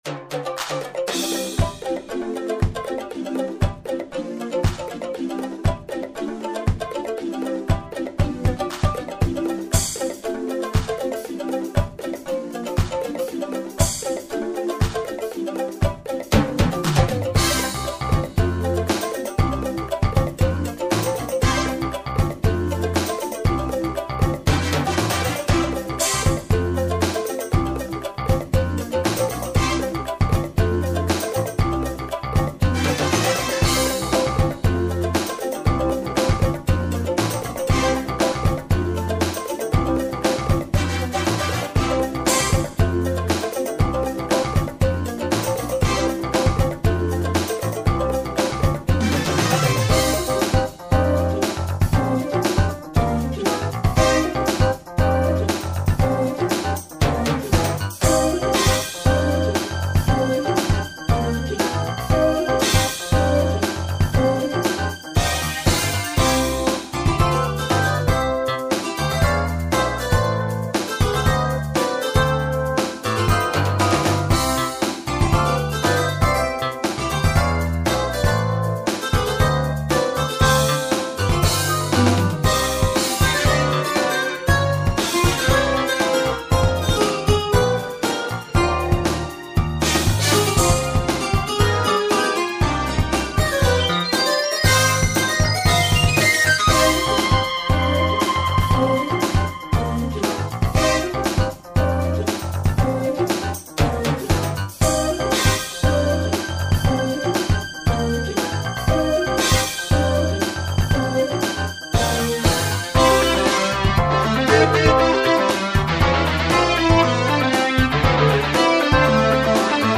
FULL VERSION /128 Kbit/s stereo /